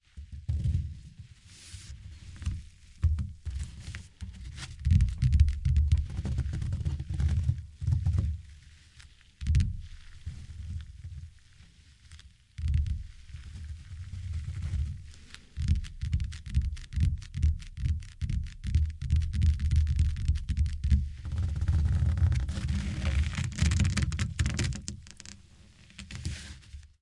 descargar sonido mp3 agarrar 1
sujetar-agrrar-1-.mp3